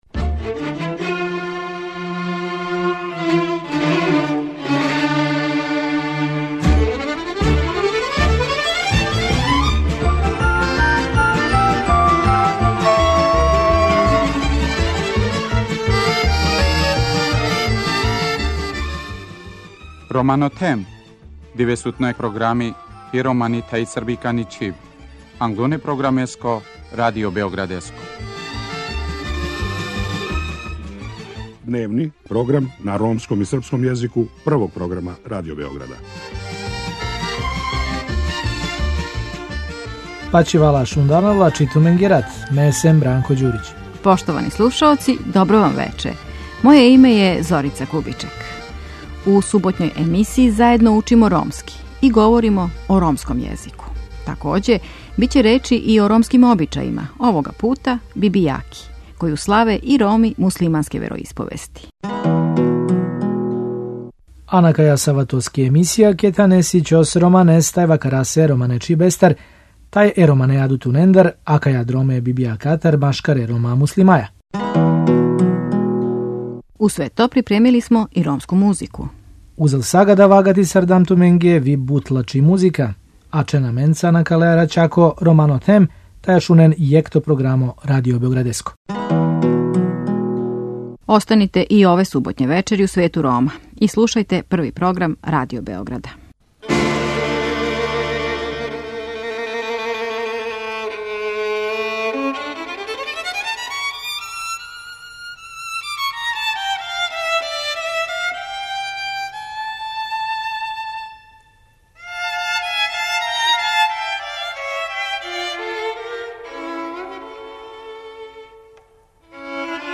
И у овој суботњој емисији уз доста добре ромске музике, заједно учимо ромски и говоримо о ромском језику.